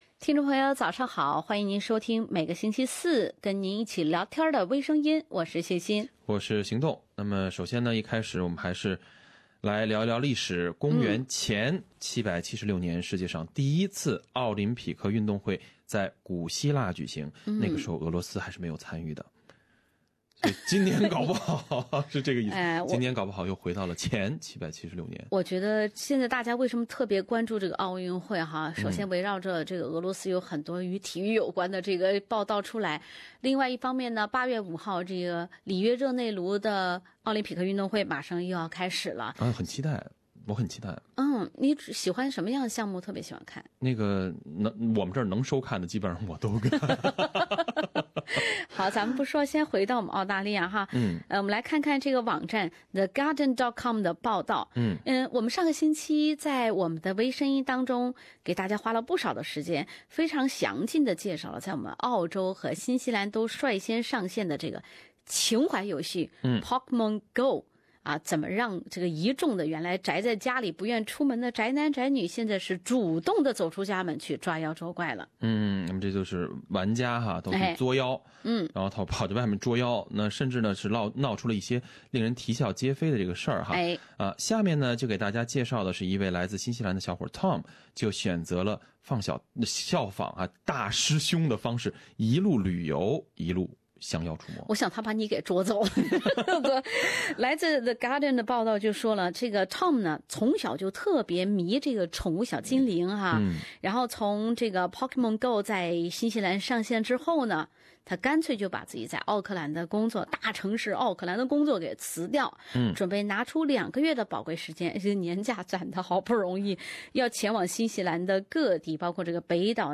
另类轻松的播报方式，深入浅出的辛辣点评；包罗万象的最新资讯；倾听全球微声音。